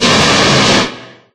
Fire5.ogg